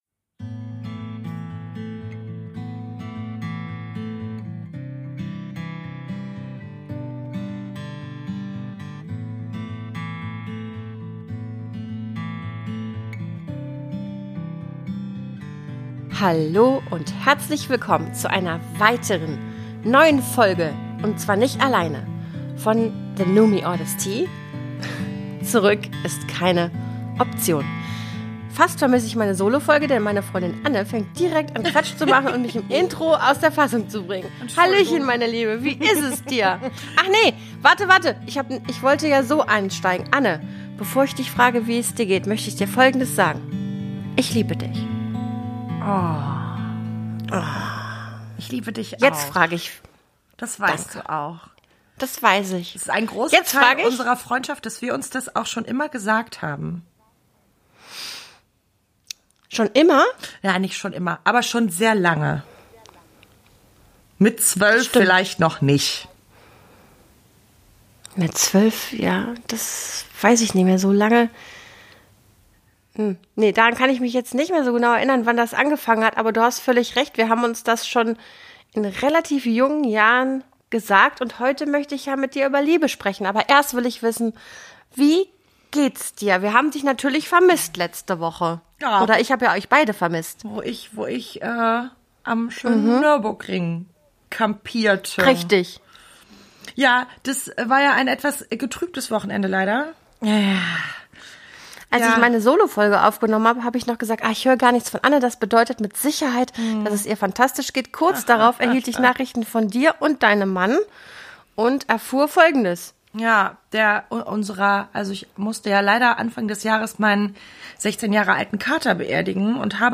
Das schönste Thema, über das man überhaupt sprechen kann. Ein Privileg es unter Freundinnen tun zu dürfen.